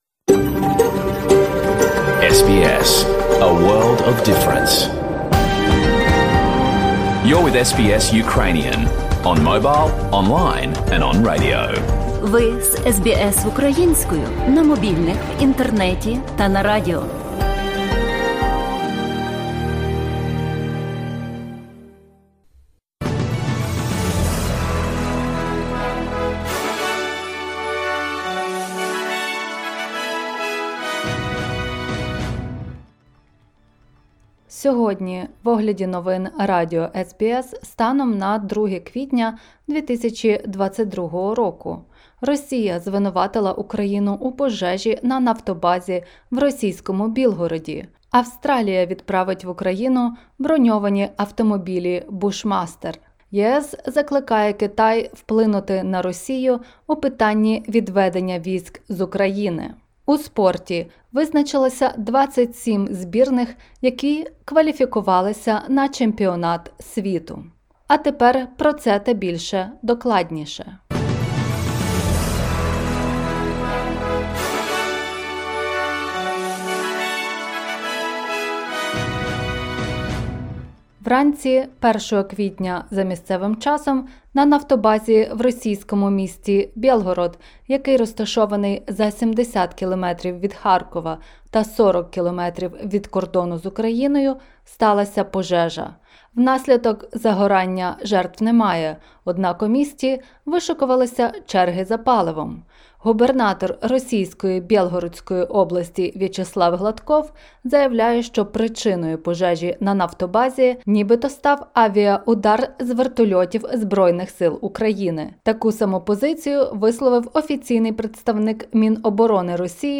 Огляд новин станом на 2 квітня 2022 року.